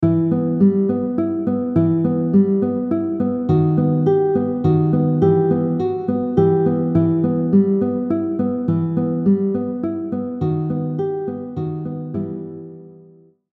guitar audio track